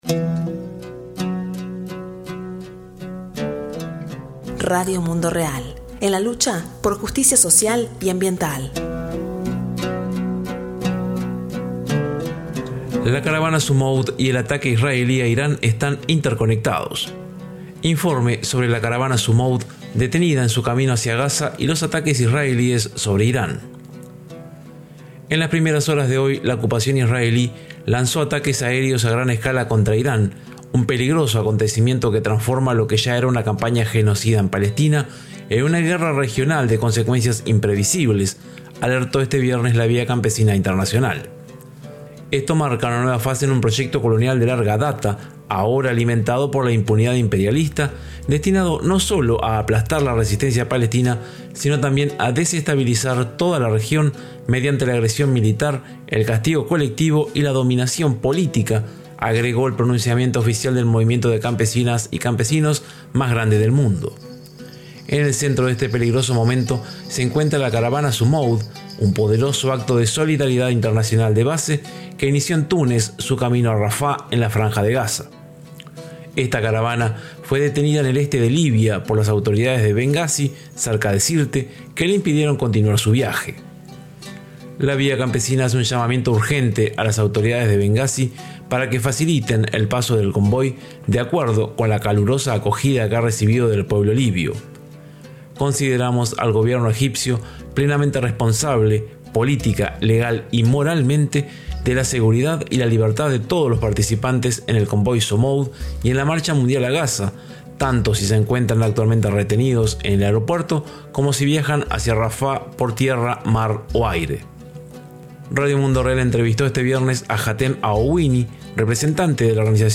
Informe sobre la Caravana Sumoud detenida en su camino hacia Gaza y los ataques israelíes sobre Irán